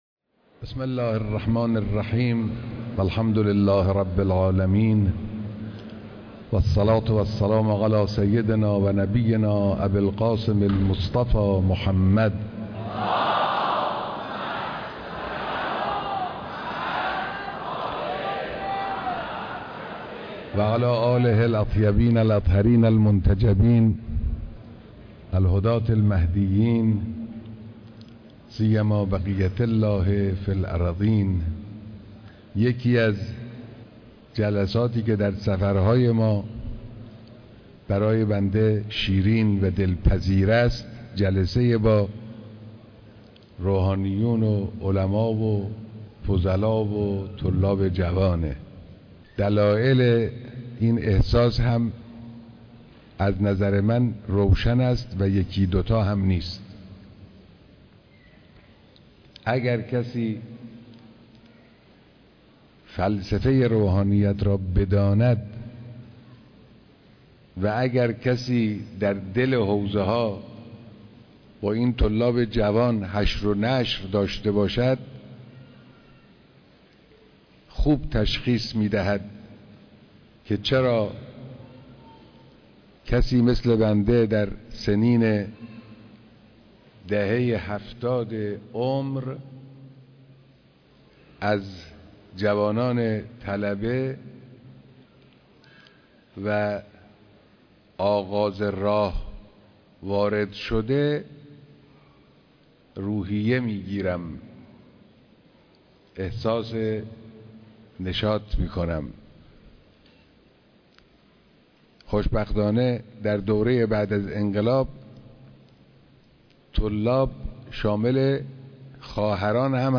بيانات در دیدار با روحانیون و طلاب استان خراسان شمالی